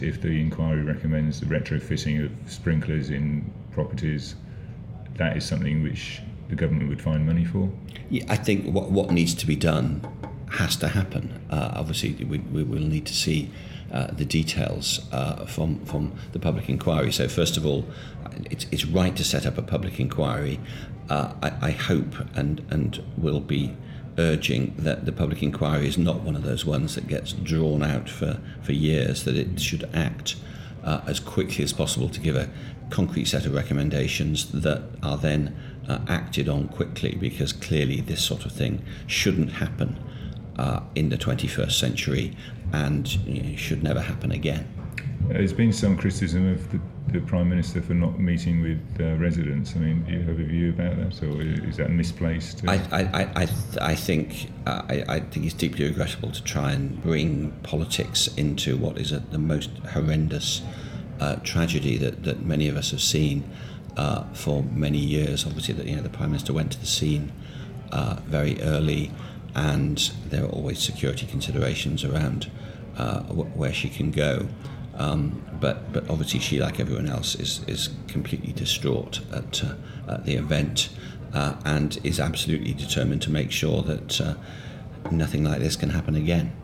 INTERVIEW: Damian Green, Ashford MP and First Secretary of State - 16/06/2017